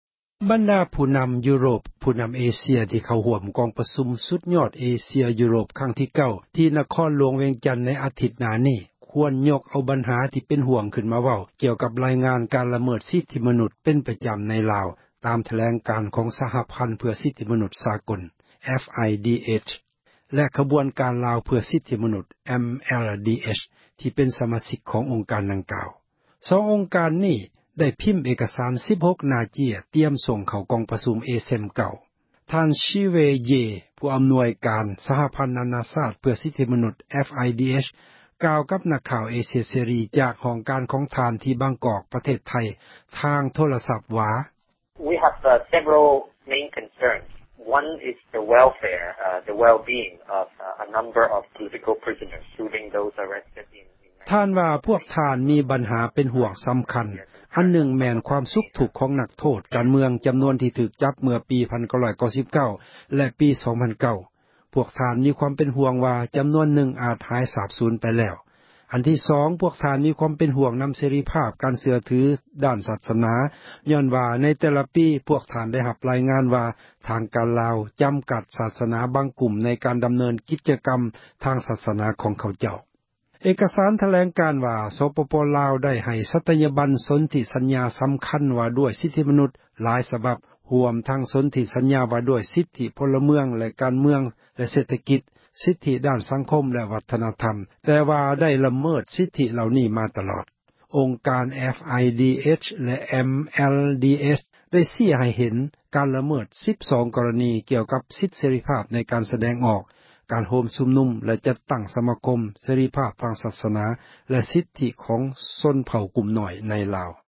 ຜ່ານທາງ ໂທຣະສັບວ່າ: